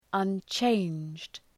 {ʌn’tʃeındʒd}